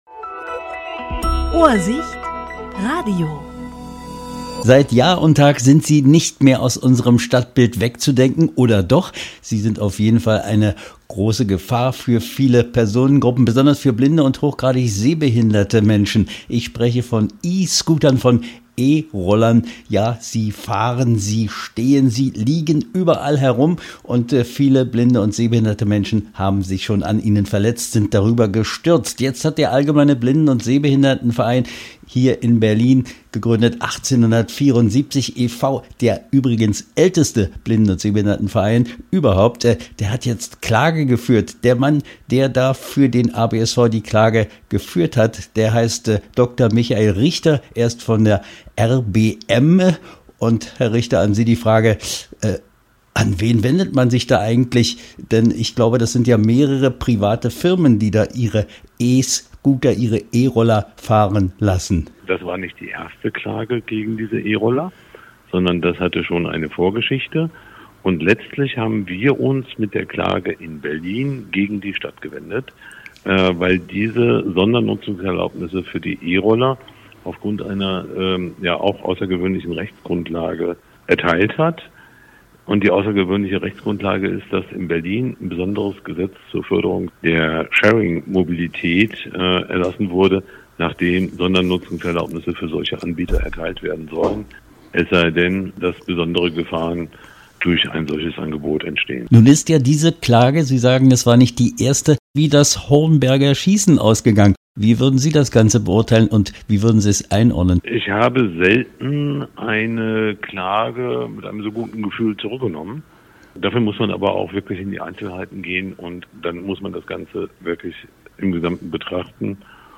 Interview 16.10.2025: ABSV-Klage Berliner E-Skooter-Chaos